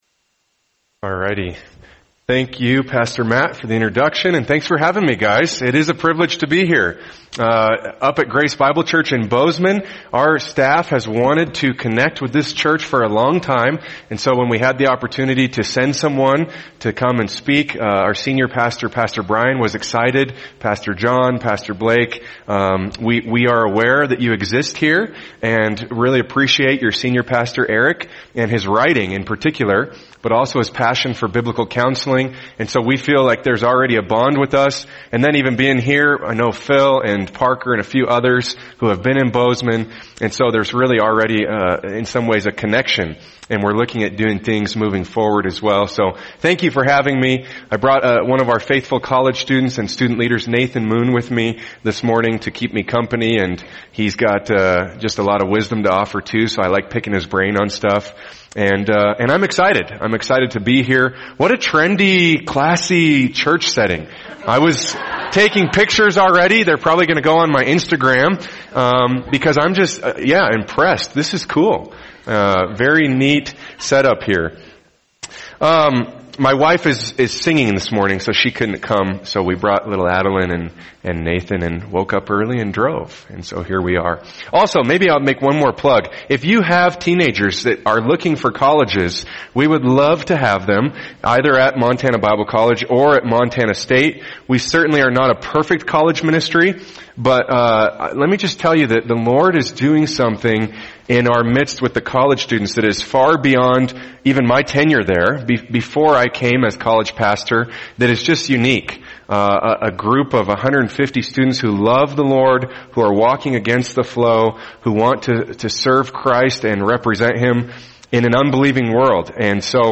[sermon] Ephesians 4:11-16 Church Growth God’s Way | Cornerstone Church - Jackson Hole